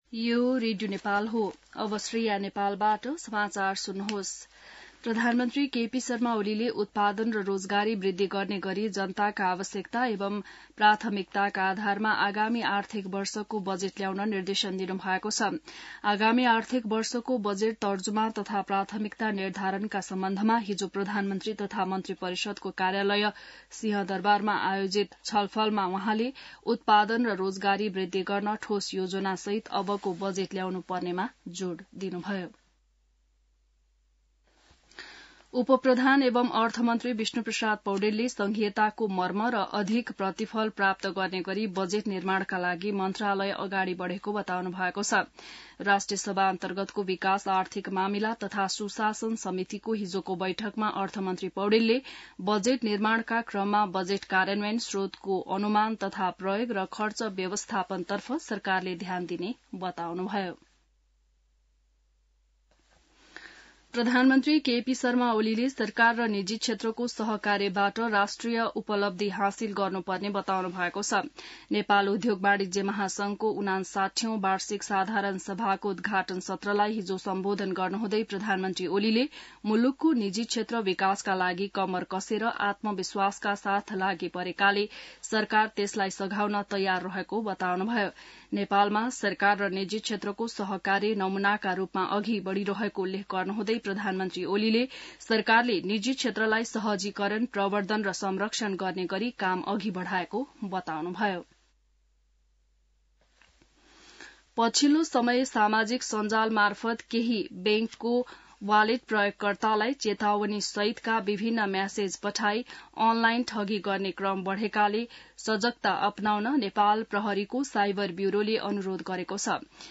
बिहान ६ बजेको नेपाली समाचार : २९ चैत , २०८१